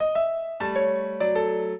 piano
minuet1-5.wav